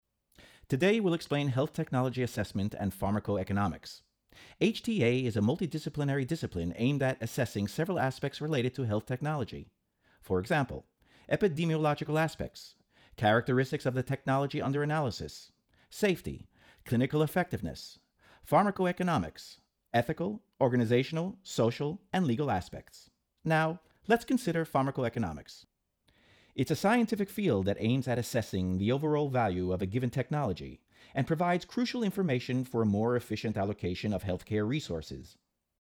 narrazione in lingua inglese.